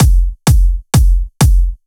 VTDS2 Song Kits 128 BPM Pitched Your Life